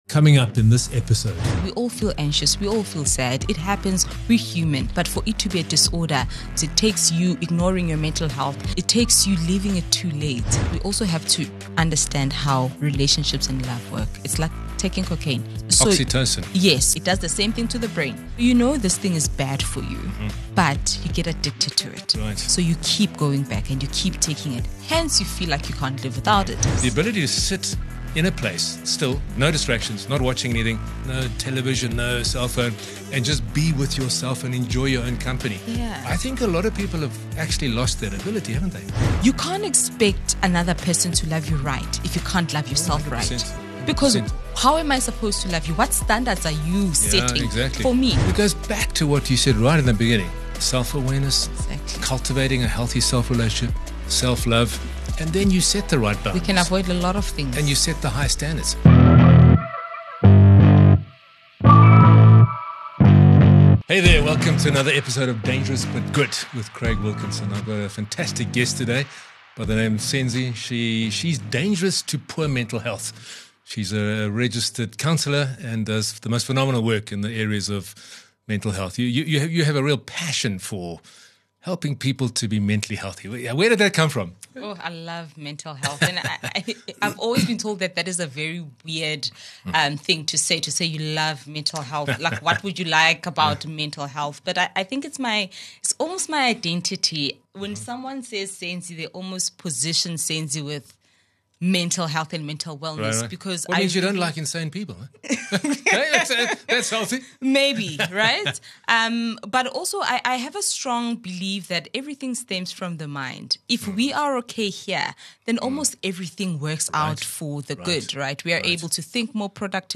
31 Jul Why You’re Still in That Toxic Relationship | A Conversation with a Mental Health Expert